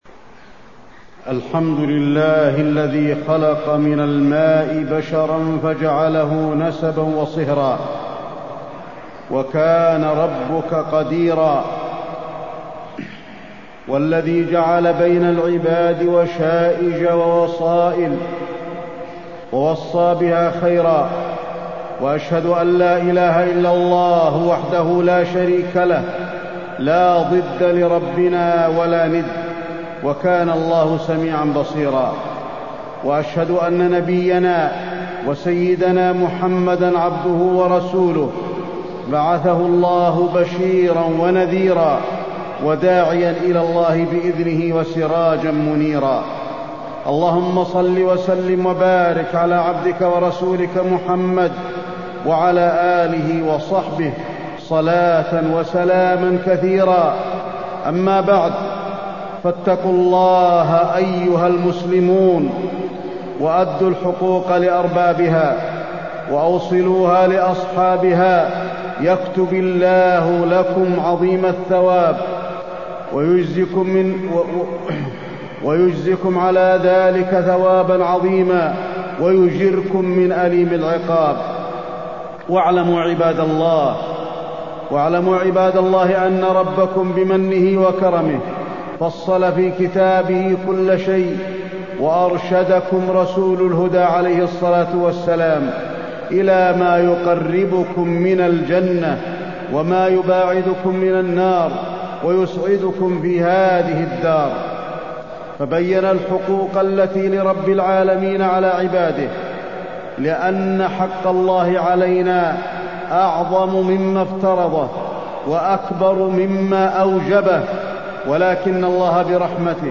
تاريخ النشر ١٩ صفر ١٤٢٥ هـ المكان: المسجد النبوي الشيخ: فضيلة الشيخ د. علي بن عبدالرحمن الحذيفي فضيلة الشيخ د. علي بن عبدالرحمن الحذيفي صلة الرحم